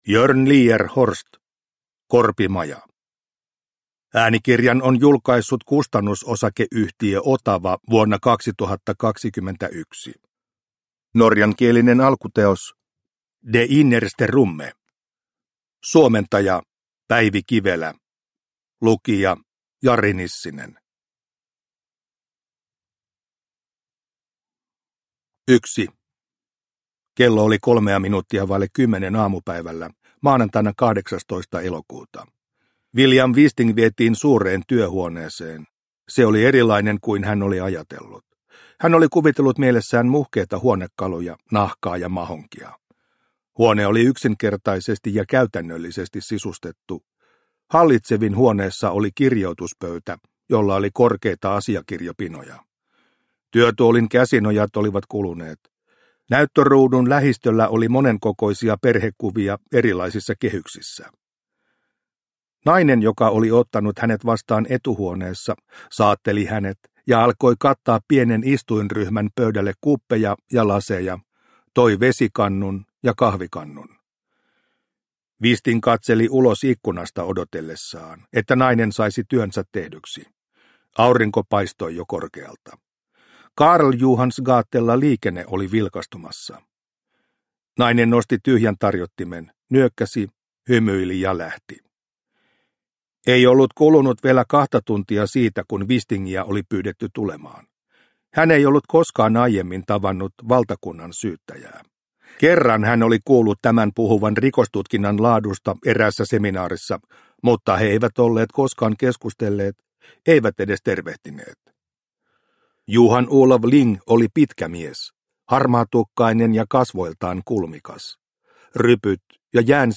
Korpimaja – Ljudbok – Laddas ner